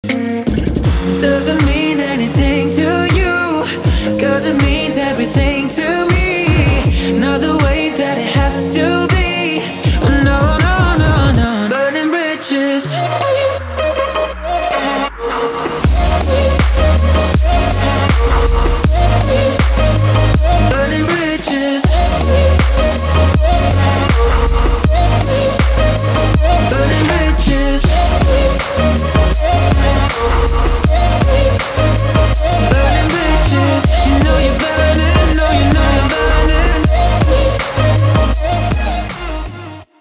• Качество: 320, Stereo
поп
dance